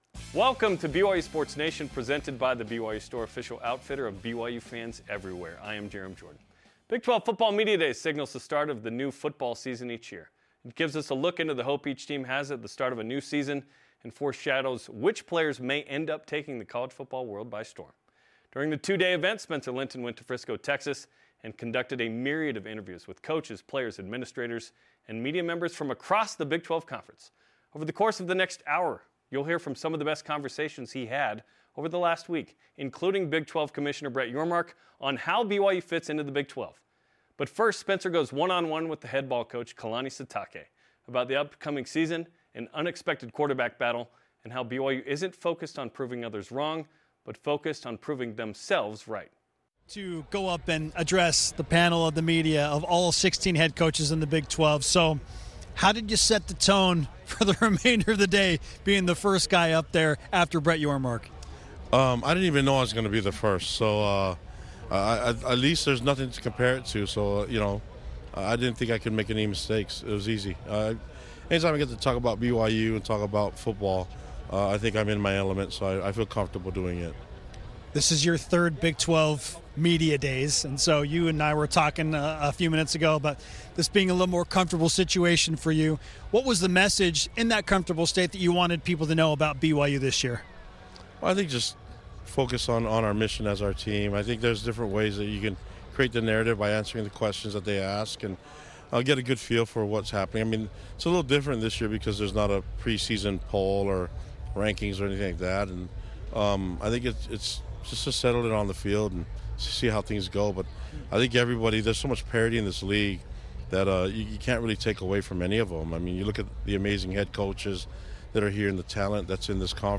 live from Big 12 Football Media Days